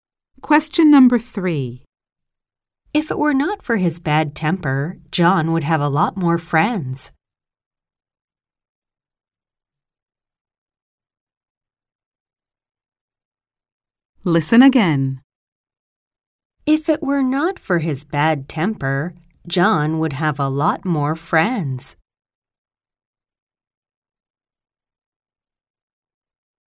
〇アメリカ英語に加えて、イギリス英語、アジア英語の話者の音声も収録しています。
〇早口で実録音したハイスピード音声をダウンロードにて提供。
ノーマル・スピード音声   ハイ・スピード音声